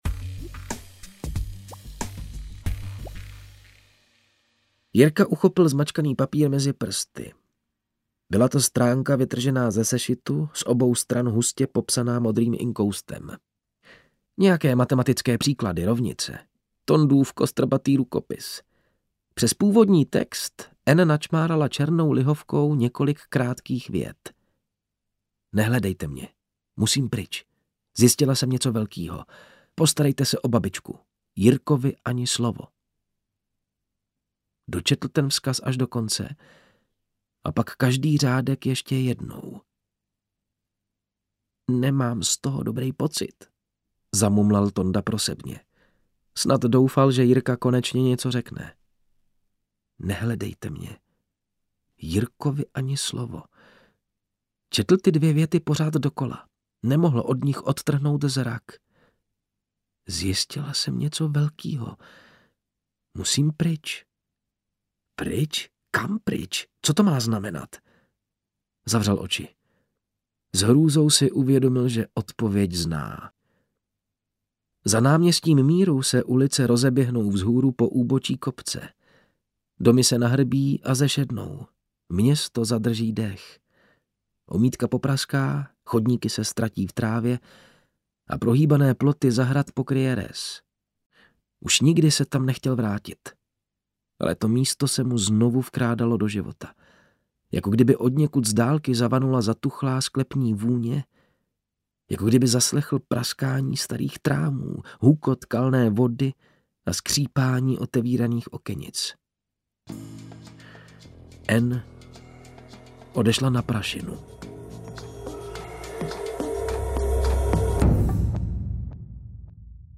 Prašina - Bílá komnata audiokniha
Ukázka z knihy